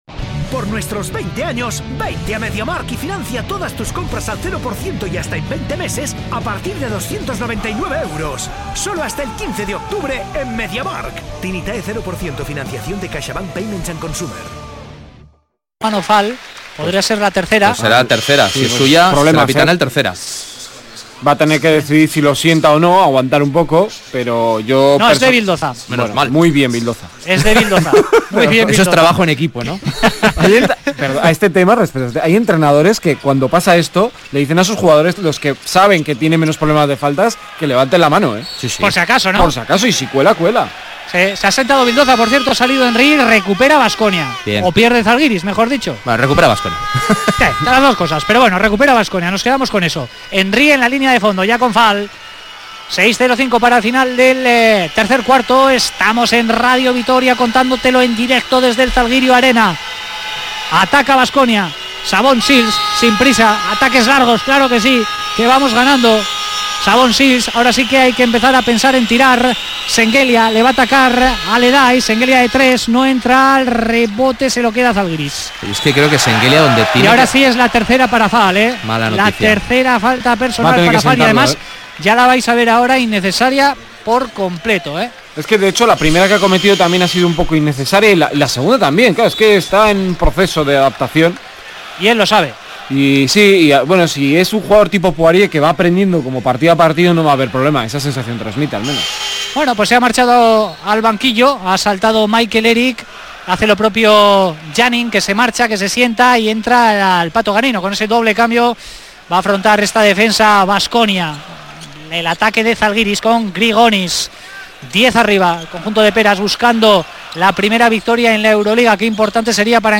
Zalgiris-Baskonia jornada 1 euroleague 2019-20 retransmisión Radio Vitoria (último cuarto)